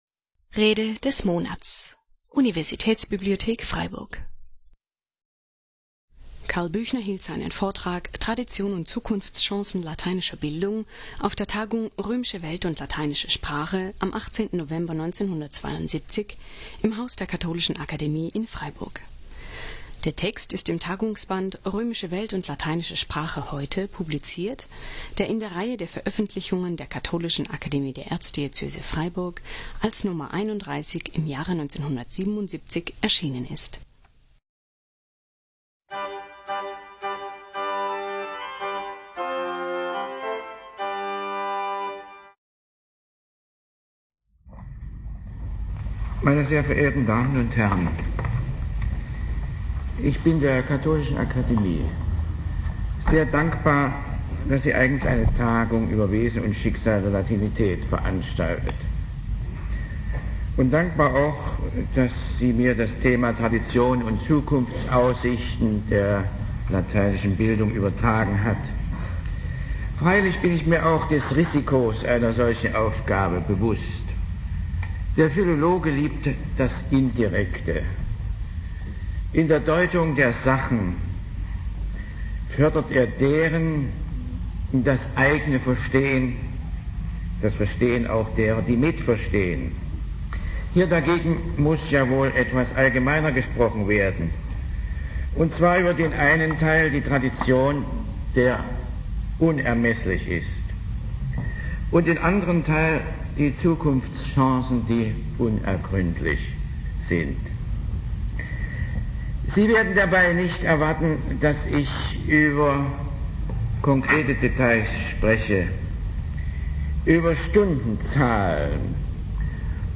Tradition und Zukunftschancen lateinischer Bildung (1972) - Rede des Monats - Religion und Theologie - Religion und Theologie - Kategorien - Videoportal Universität Freiburg